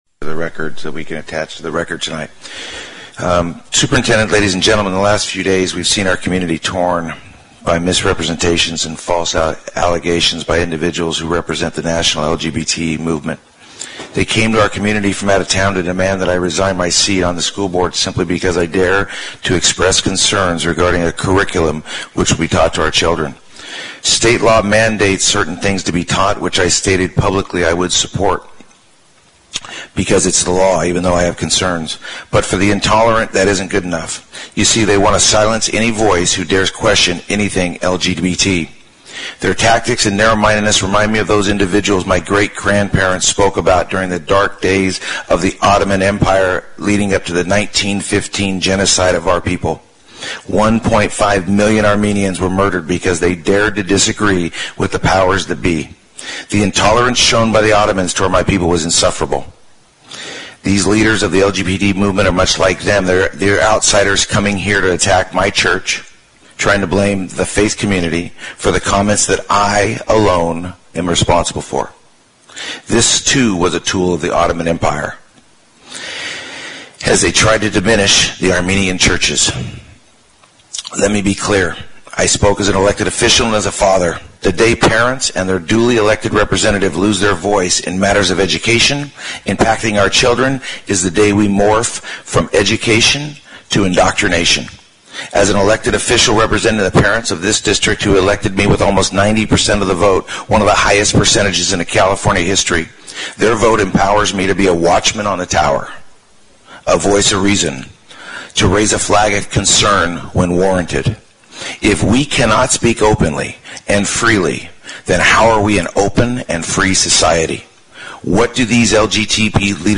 Fresno Unified School Board President Brooke Ashjian responds to calls for his resignation at board meeting on Aug. 23, 2017.
BROOKE-STATEMENT.mp3